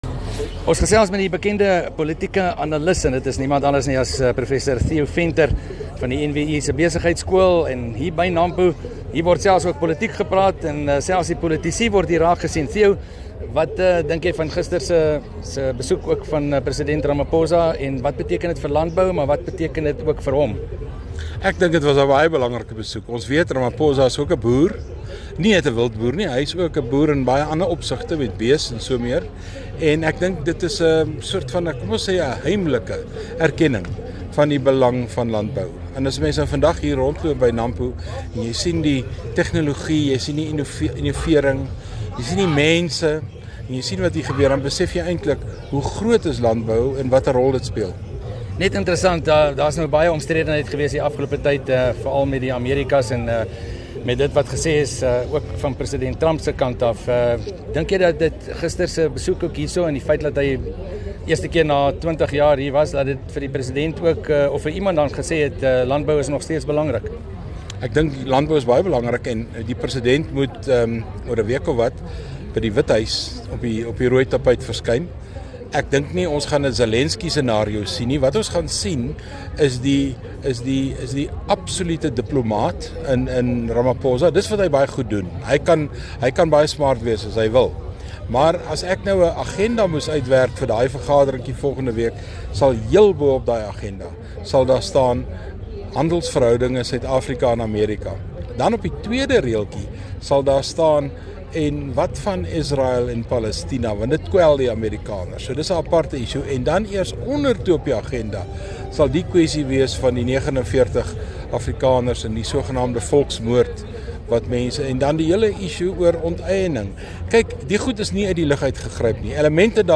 gesels op Nampo